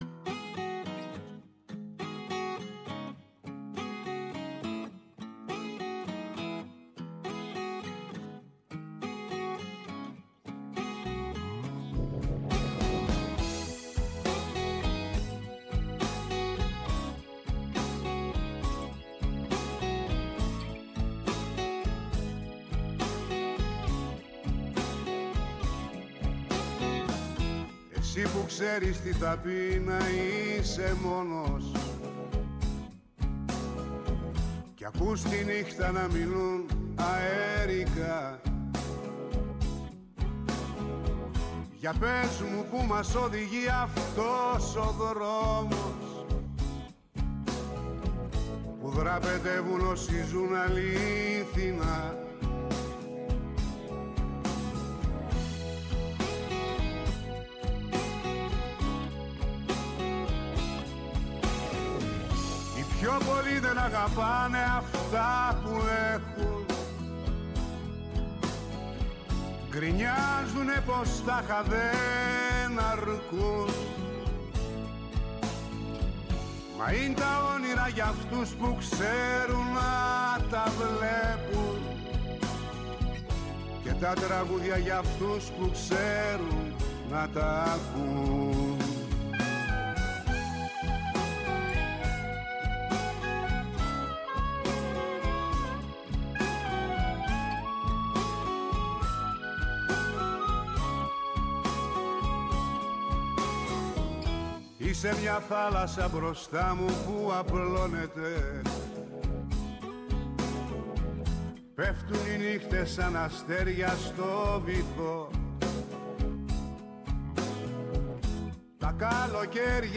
Και Ναι μεν η ματιά μας στρέφεται στην εγχώρια επικαιρότητα, Αλλά επειδή ο κόσμος “ο μικρός ο μέγας” -όπως το διατύπωσε ο ποιητής- είναι συχνά ο περίγυρός μας, θέλουμε να μαθαίνουμε και να εντρυφούμε στα νέα του παγκόσμιου χωριού. Έγκριτοι επιστήμονες, καθηγητές και αναλυτές μοιράζονται μαζί μας τις αναλύσεις τους και τις γνώσεις τους.